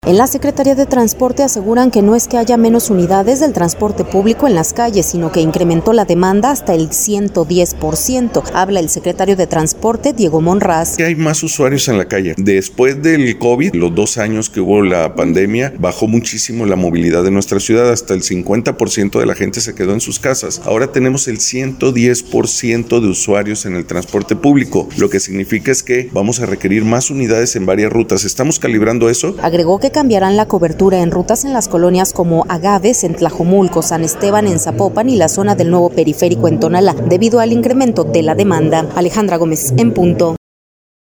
Habla el Secretario de Transporte, Diego Monraz: